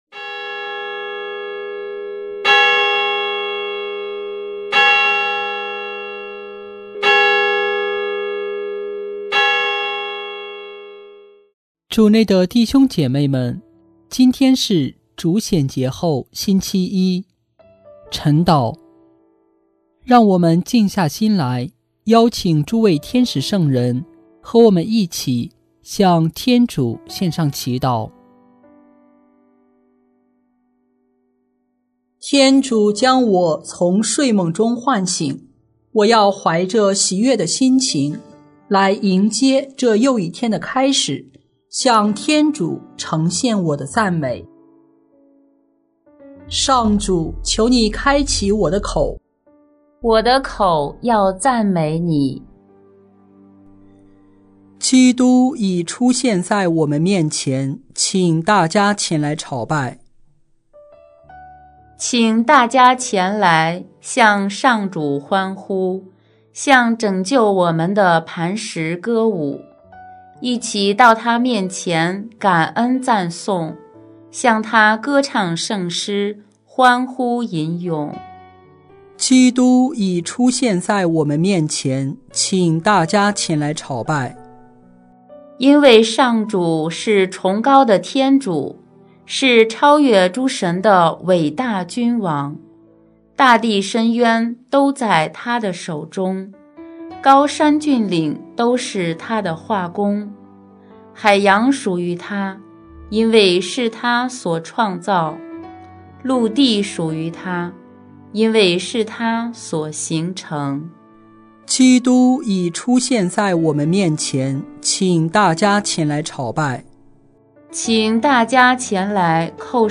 【每日礼赞】|1月5日主显节后星期一晨祷